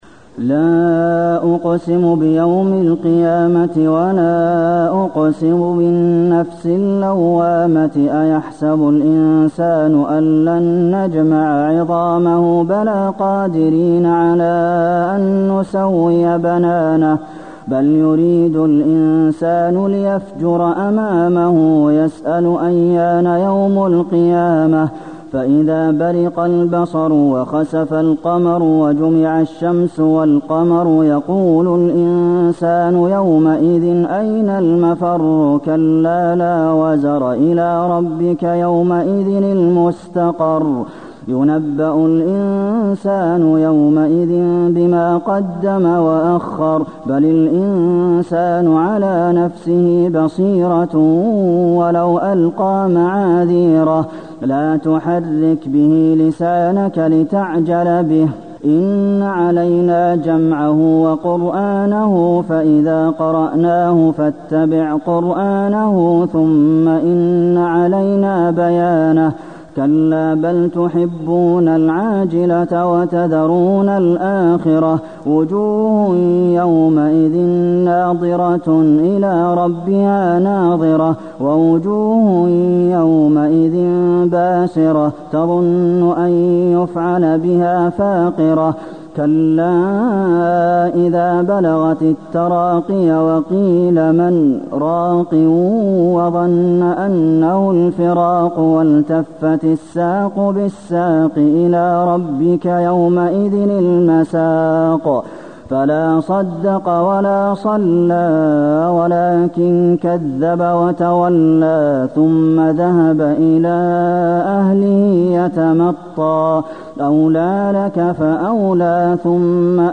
المكان: المسجد النبوي القيامة